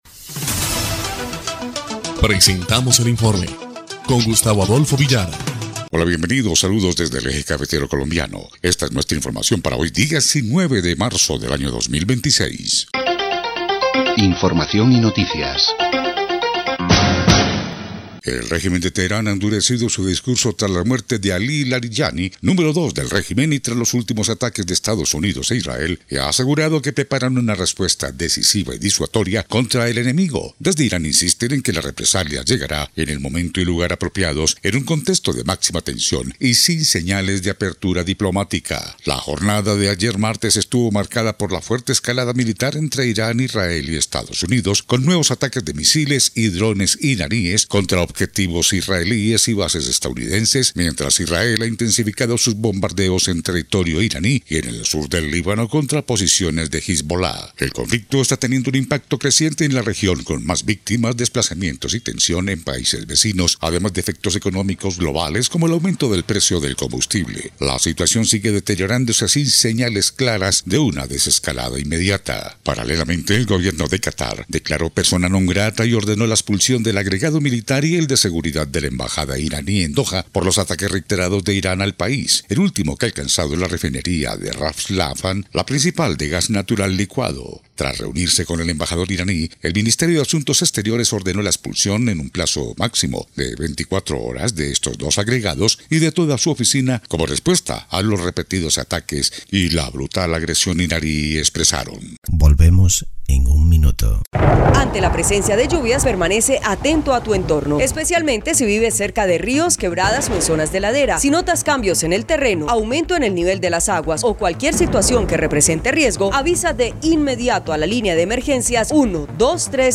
EL INFORME 2° Clip de Noticias del 19 de marzo de 2026